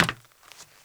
METAL 2B.WAV